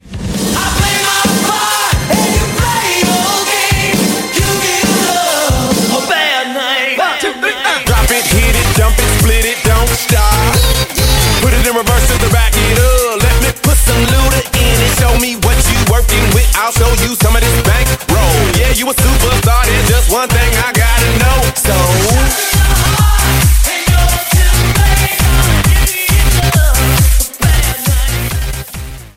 Rock Metal Alternative Gothic Pop Tune Instrumental